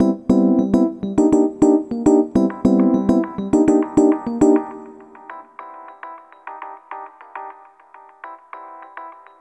Ala Brzl 1 Fnky Piano-A.wav